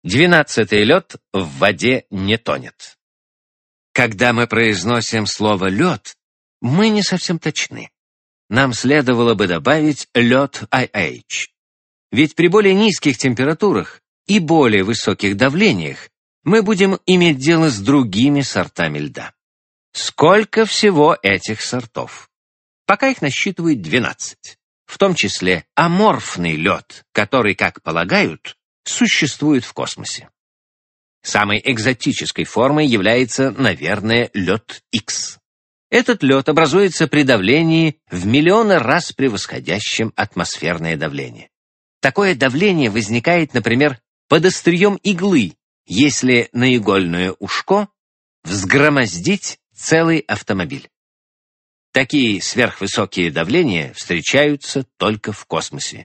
Аудиокнига Величайшие природные явления | Библиотека аудиокниг